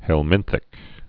(hĕl-mĭnthĭk)